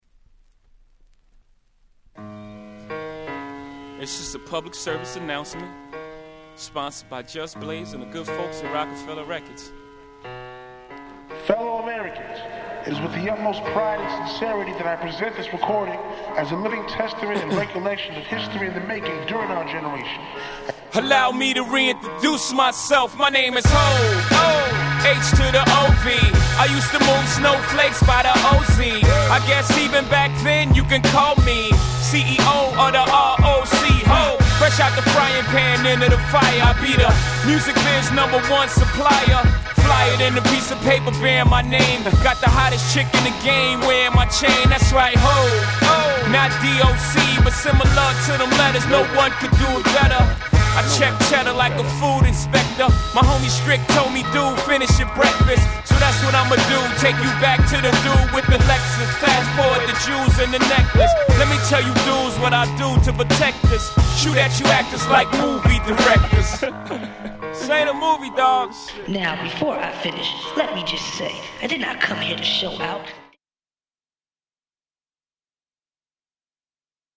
03' Big Hit Hip Hop !!